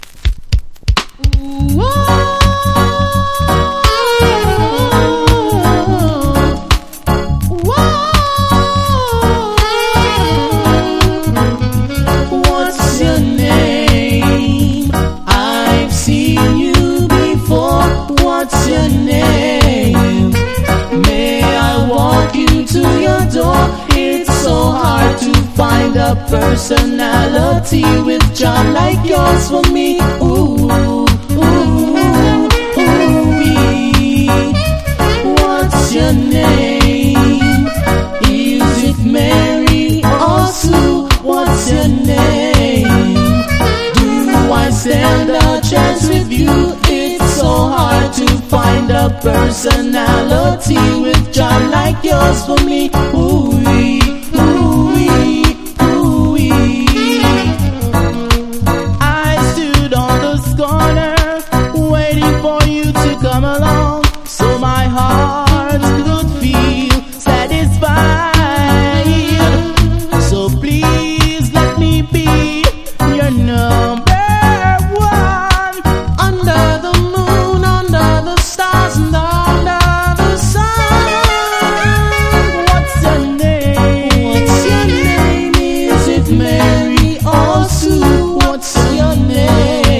• REGGAE-SKA
# LOVERS# DANCE HALL
(ジャマイカ盤特有のチリノイズ入ります)